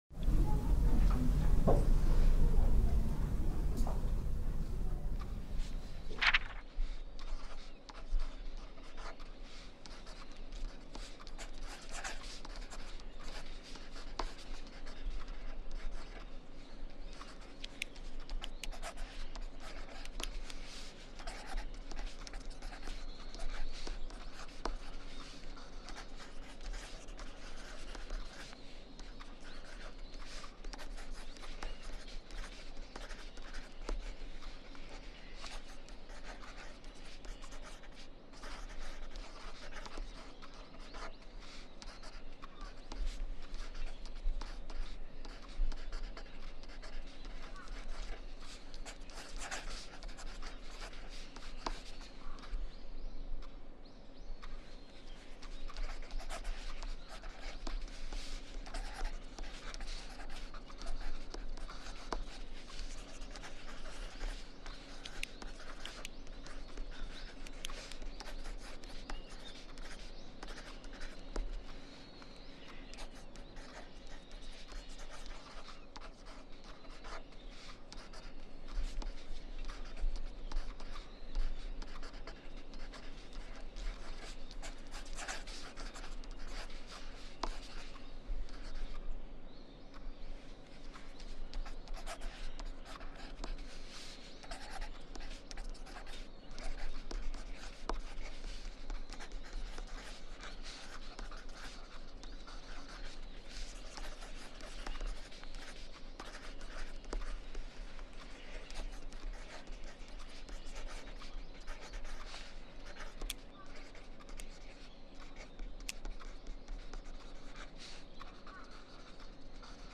Exam Room Writing Sounds – 80-Minute Deep Focus Session